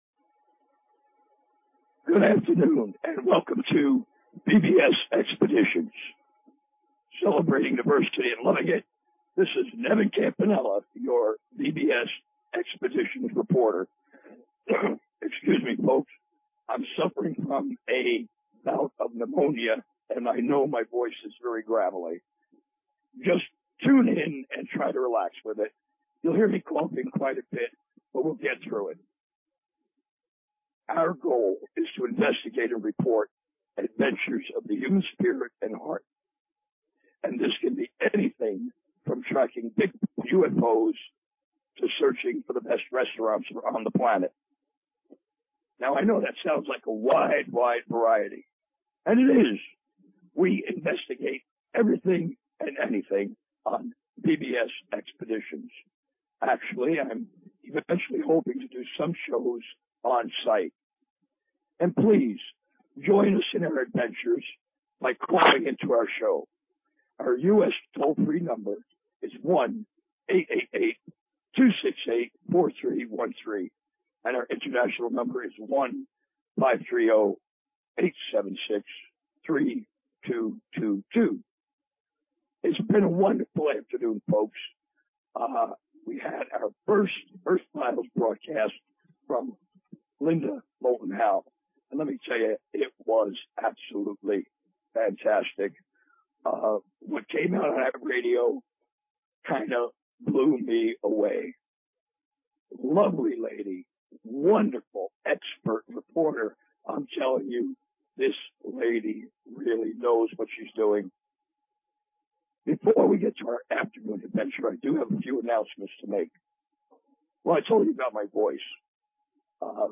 Talk Show Episode, Audio Podcast, BBS_Expeditions and Courtesy of BBS Radio on , show guests , about , categorized as